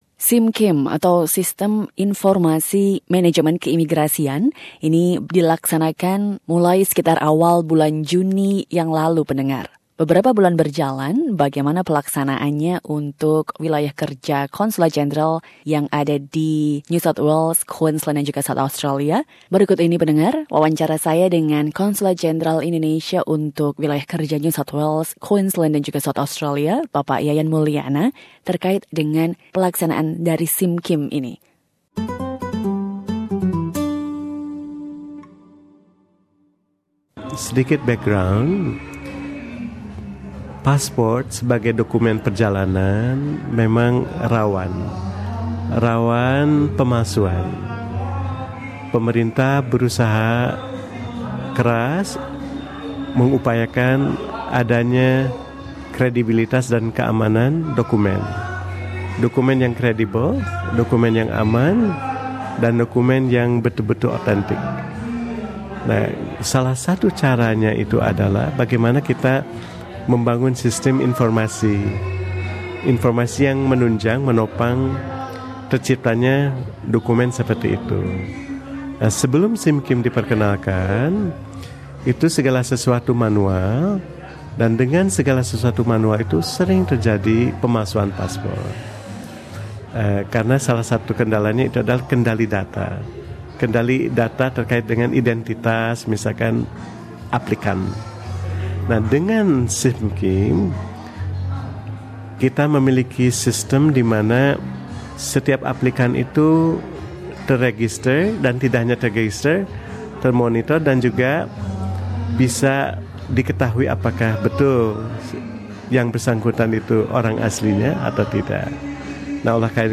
Wawancara dengan Bpk Yayan Maulana Konsul Jenderal untuk NSW QLD dan SA mengenai SIMKIM sistim imigrasi yang dipakai untuk menangani paspor Indonesia.